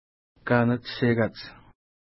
ID: 268 Longitude: -63.4700 Latitude: 56.1631 Pronunciation: ka:nəttʃeka:ts Translation: Where The Rock Wall Ends Feature: river Explanation: The name refers to the fact that the steep-walled valley ends upstream at the end of the lake.